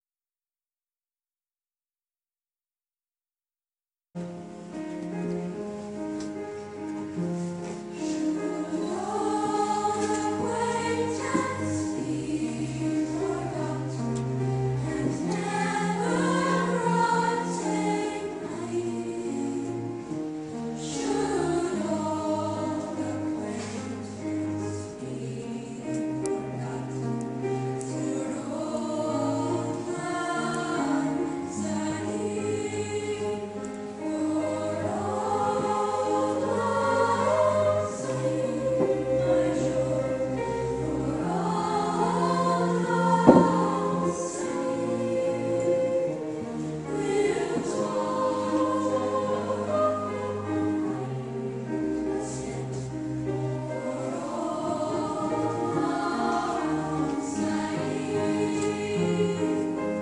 I am hoping on hope that someone might help me with an audio recording of a choir singing “Auld Lang Syne”. Despite the fact that the recording is fairly clear, there is still some hiss, a few clicks, and on one or two occassions there are coughs from the audience and either someone closing a door or moving a chair.